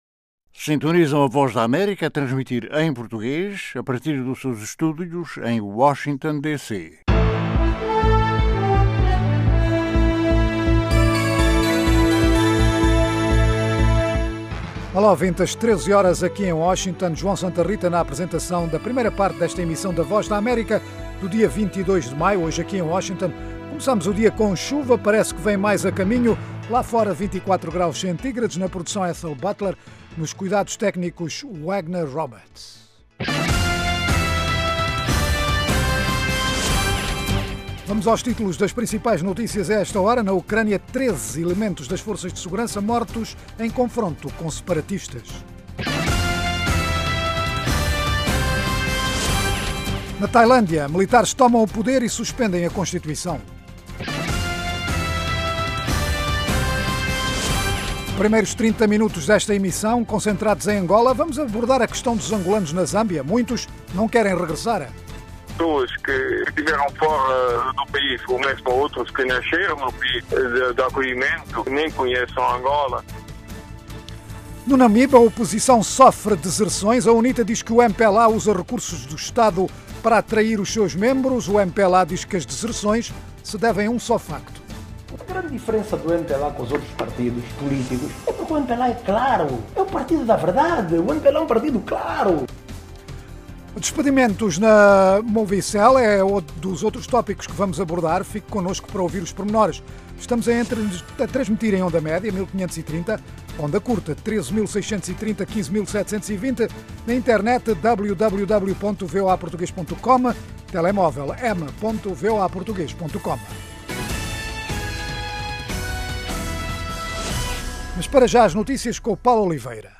Um programa orientado aos países Lusófonos de África, sem esquecer o Brasil e comunidades de língua portuguesa noutras partes do mundo. Oferece noticias, informação e analises e divide-se em três meias-horas: 1) Orientado a Angola - com histórias enviadas de Angola, por jornalistas em todo o país sobre os mais variados temas. 2) Notícias em destaque na África lusófona e no mundo, 3) Inclui as noticias mais destacadas do dia, análises, artes e entretenimento, saúde, questões em debate em África.